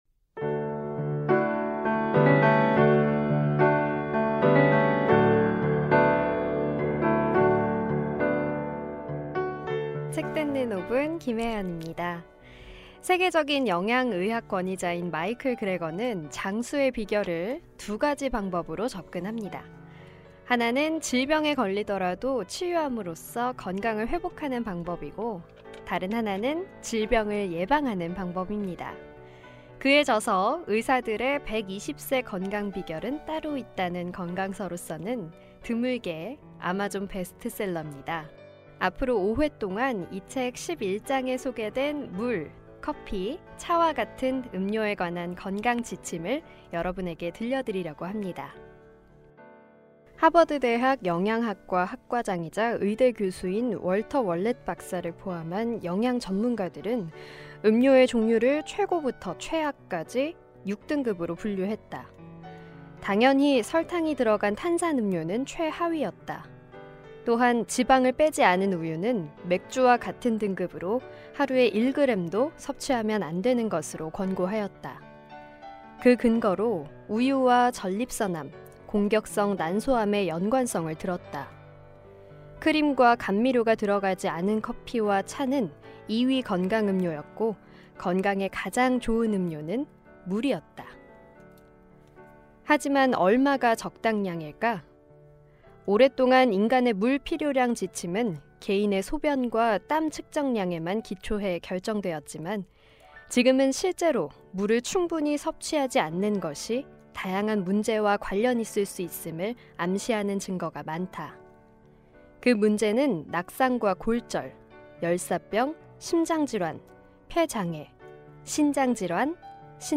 북 큐레이터